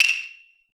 TM88 RnBPerc.wav